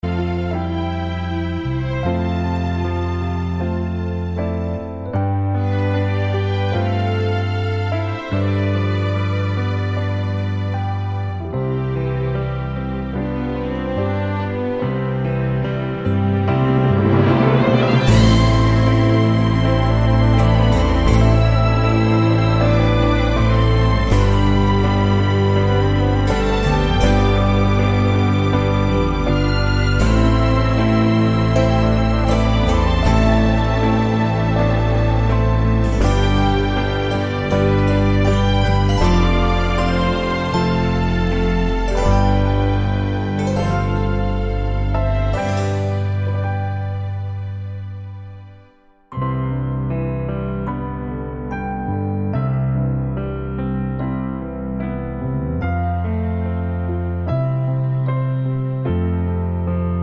Medleys